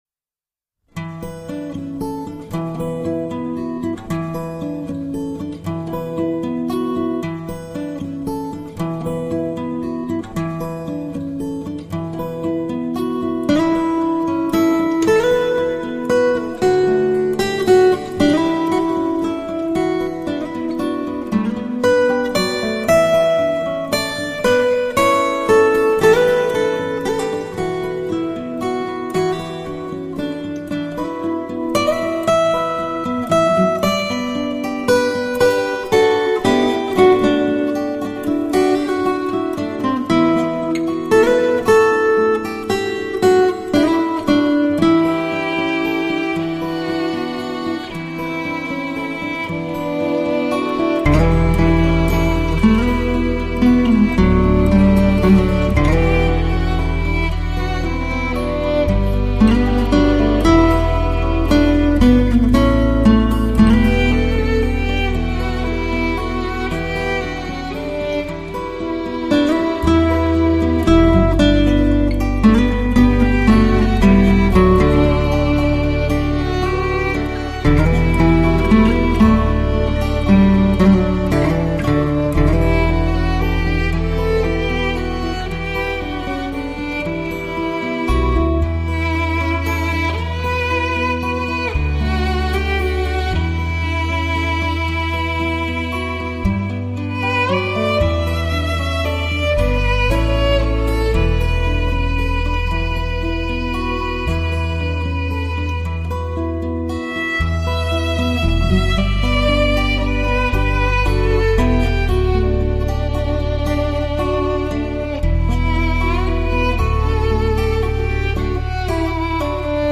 HiFi吉他典范
的组合，空气感和速度上佳，中音圆润、高频则更为明亮通透。
石般指法呈现眼前，是一张不折不扣的原声吉他发烧录音。